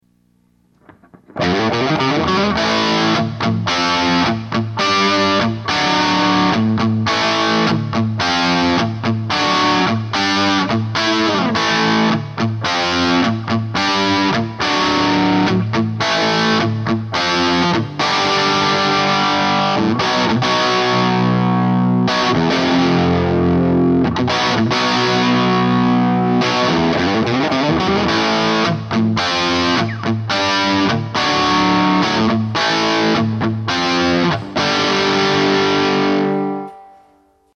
Guitar: Ibanez RG570 - Bridge: Duncan Distortion - Neck:Ibanez C2 single coil
Speaker: 1978 Peavey 412 with 200 watt Peavey Scrpions - 4 ohms
SM57 -> Audiobox USB -> Cubase LE4
Same as above but with cascade off.
cascade2_off.mp3